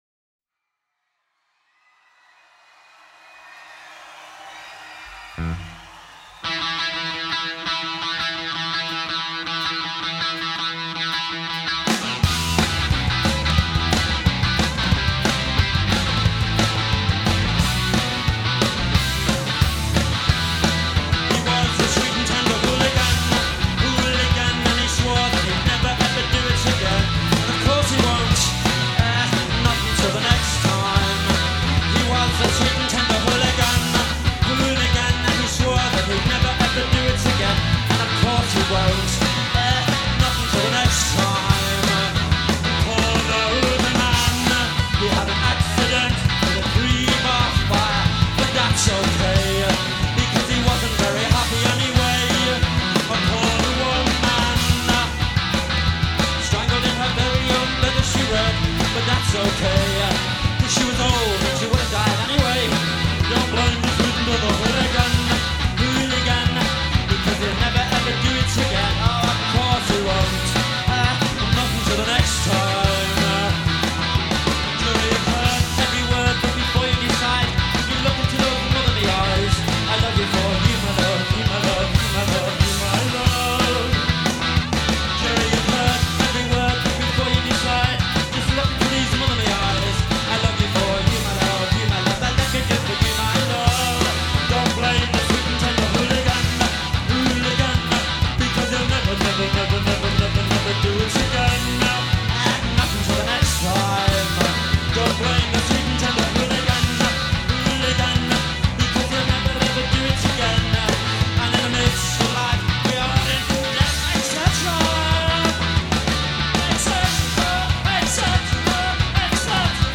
at his very first live show in Wolverhampton in 1988